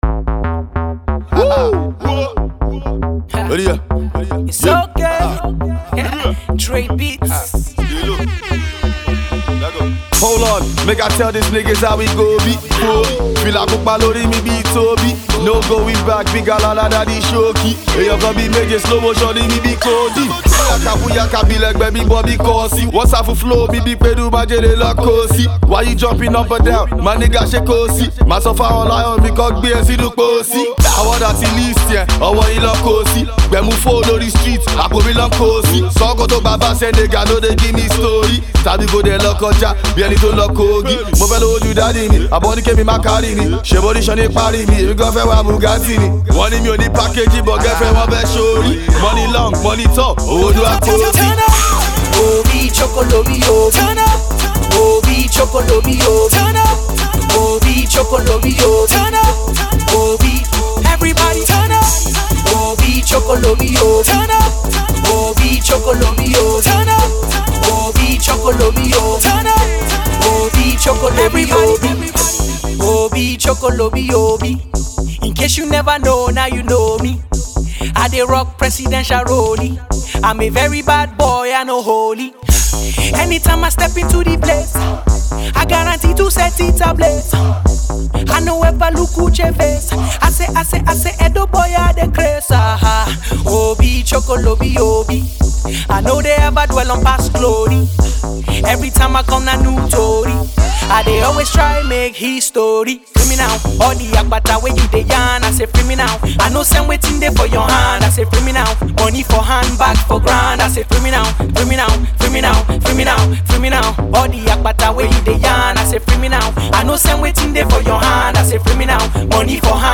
Street Rap